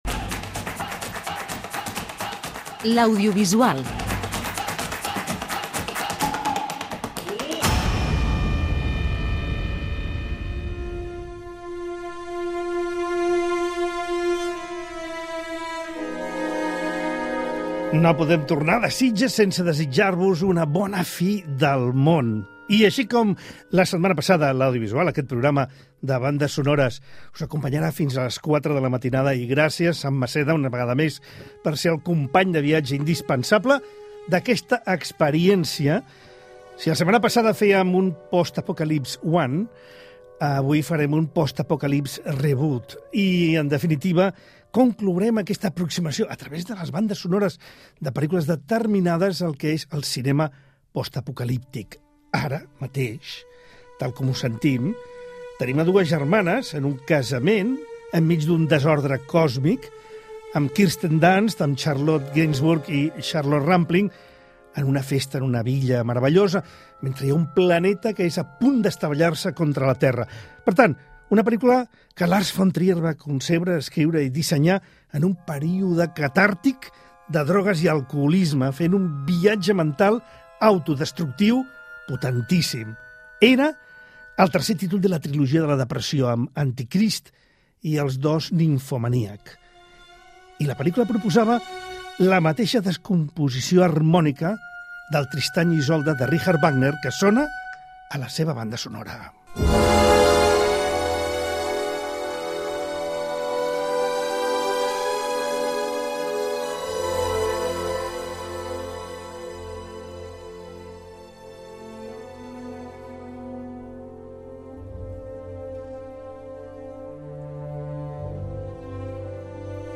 ens ofereix un programa especial de bandes sonores relacionades amb el cinema fant�stic